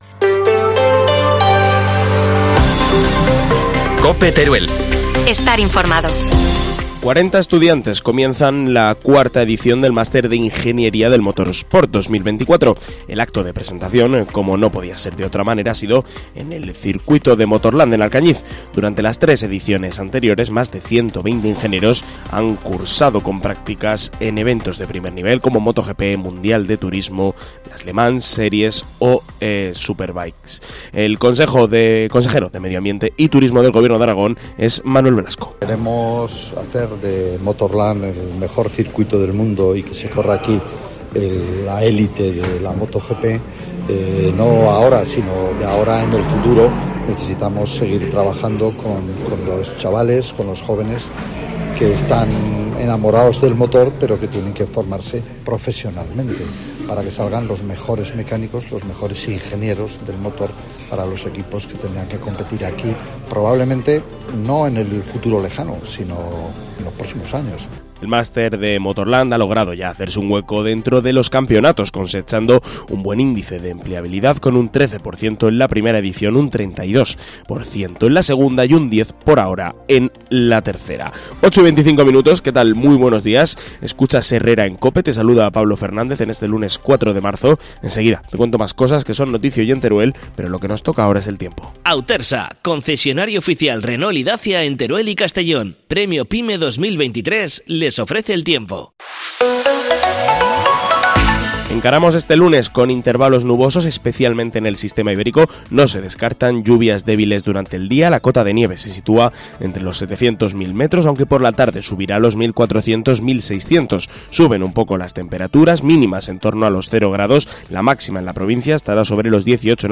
AUDIO: Titulares del día en COPE Teruel